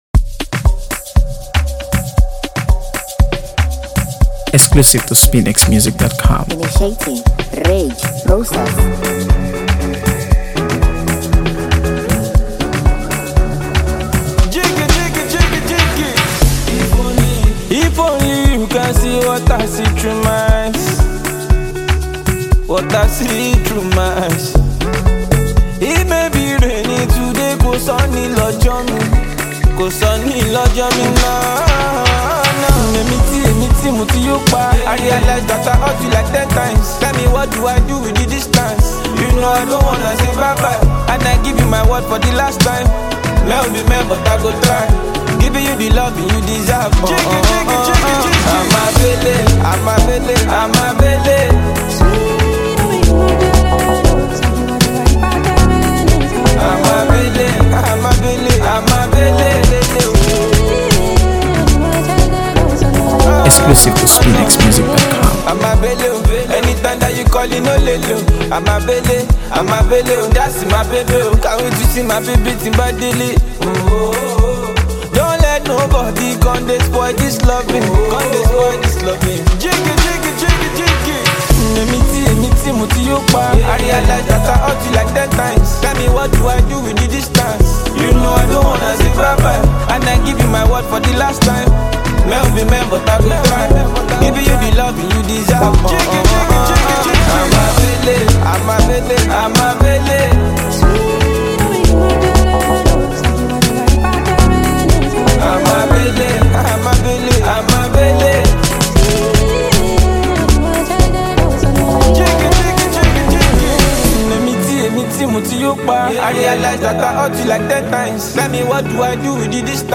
AfroBeats | AfroBeats songs
expertly layered beats